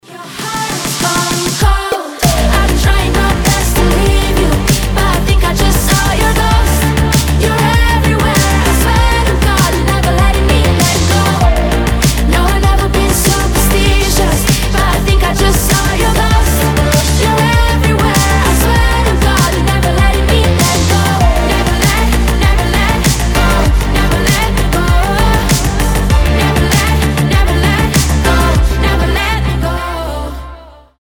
• Качество: 320, Stereo
женский голос
Dance Pop
Electropop